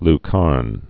(l-kärn)